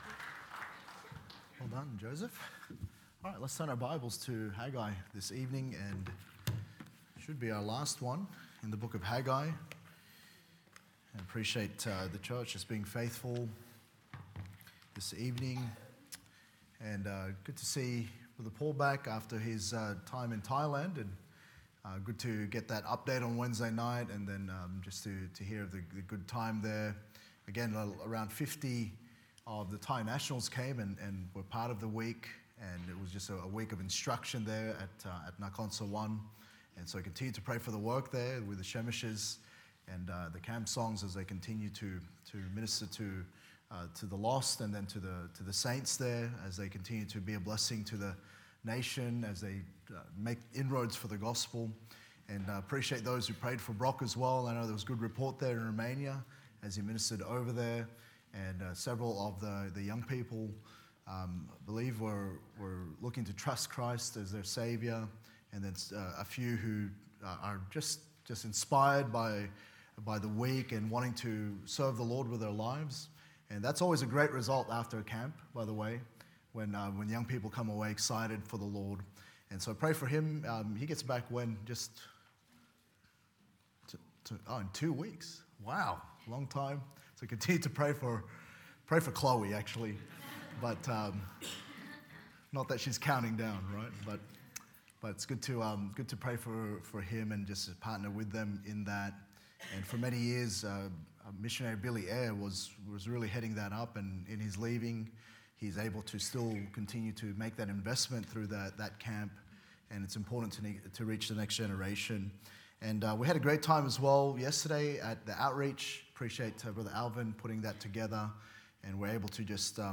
Refocus Current Sermon